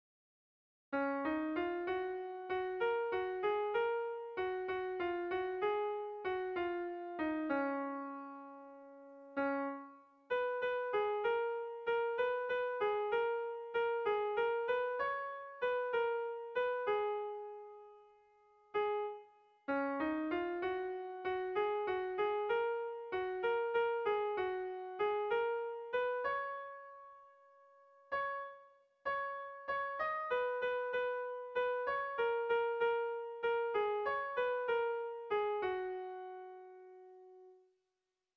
Sentimenduzkoa
Zortziko handia (hg) / Lau puntuko handia (ip)
ABDE